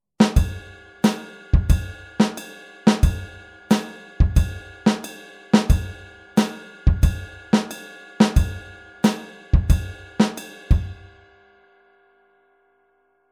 Rechte Hand wieder auf dem Kopfbecken